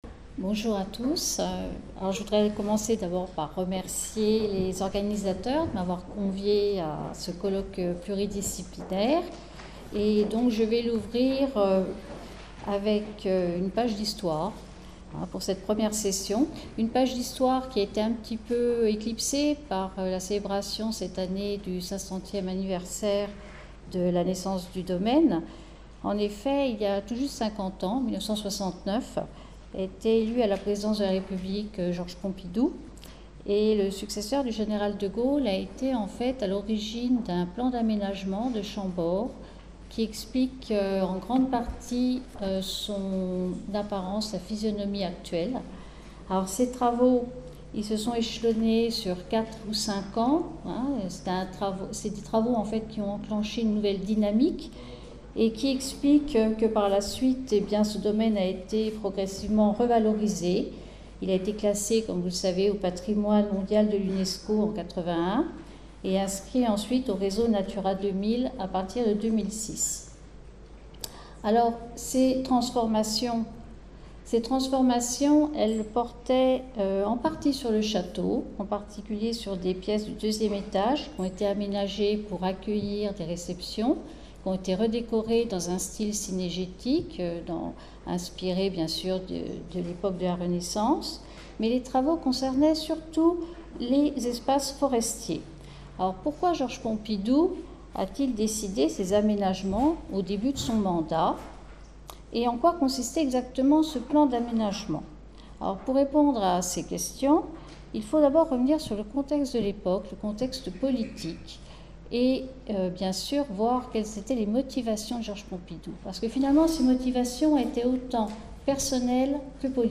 Ce colloque international, qui s'est tenu au sein du Domaine national de Chambord (26 et 27 mars 2019), s'inscrit dans le cadre du projet de recherche COSTAUD (Contribution des OnguléS au foncTionnement de l’écosystème et AUx services rendus à ChamborD, financé par la Région Centre-Val de Loire et porté par l'Irstea, 2016-2019).